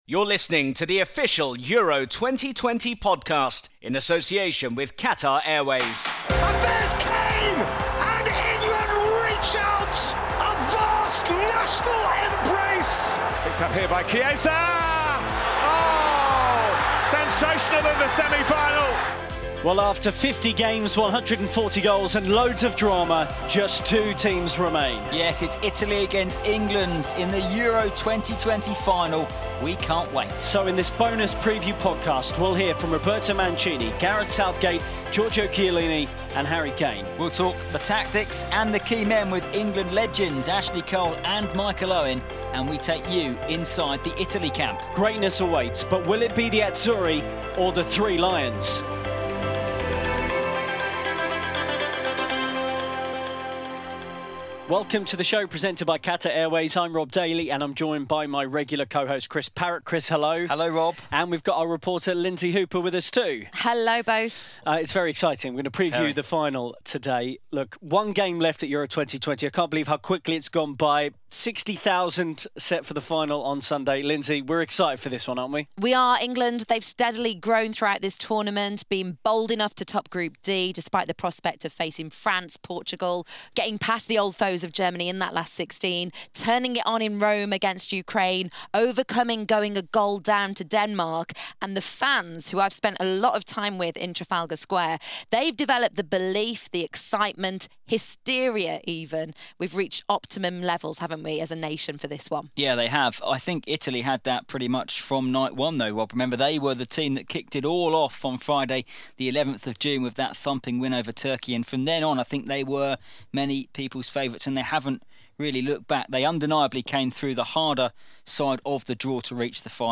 Facebook Twitter Headliner Embed Embed Code See more options In this bonus podcast, we hear from Giorgio Chiellini, Harry Kane, Roberto Mancini and Gareth Southgate as the clock ticks down to the EURO 2020 final between Italy and England. We discuss tactics and key men, chat with England legends Ashley Cole and Michael Owen, and we take you inside the Azzurri camp ahead of the Wembley showpiece.